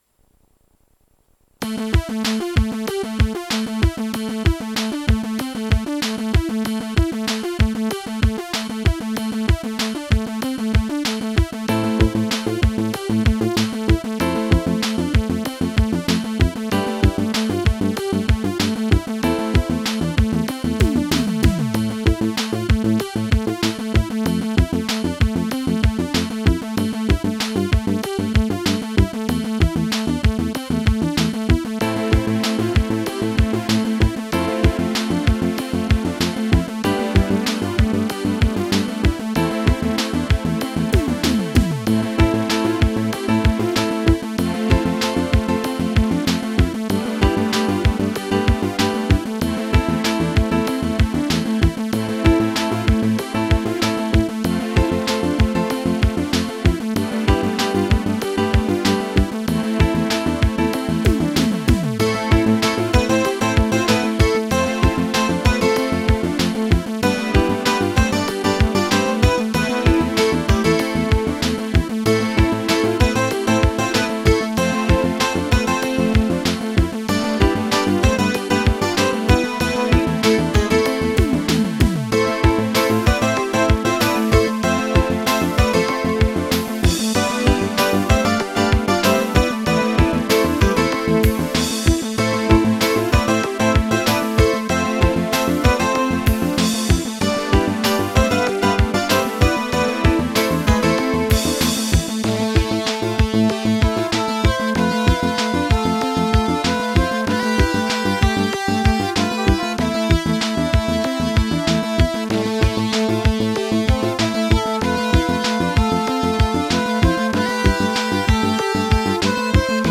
ちょっと怪しげな曲です。テーマとかイメージとかあまり考えずに淡々と創ったので、淡々とした曲になってます。”